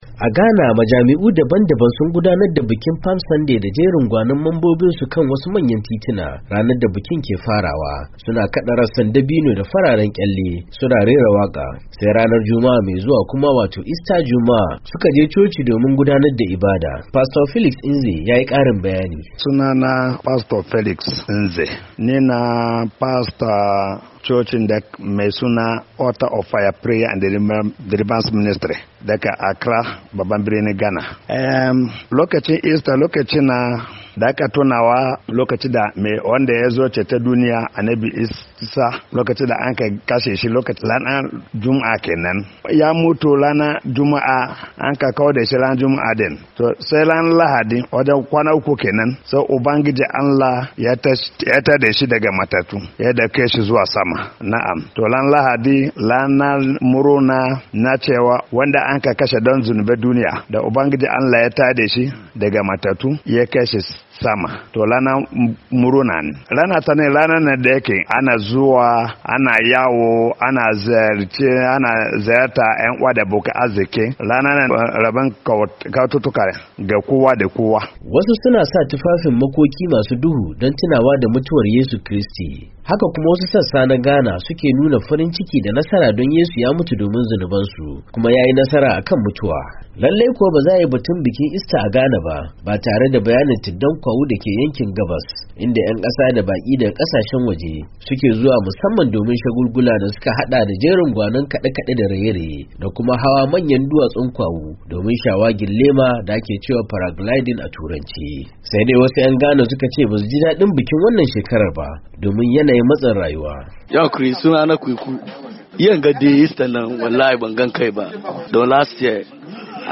Accra, Ghana —